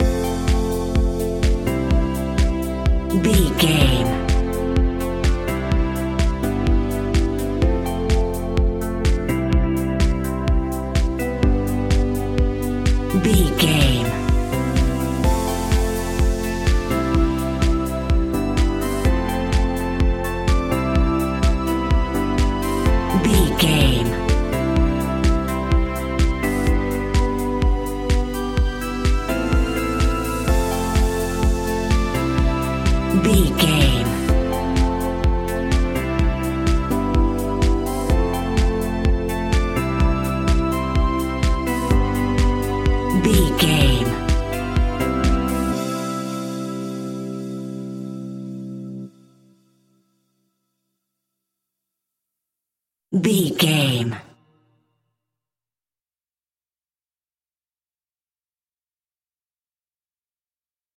Ionian/Major
groovy
uplifting
energetic
repetitive
drums
synthesiser
drum machine
electric piano
house
electro house
funky house
synth leads
synth bass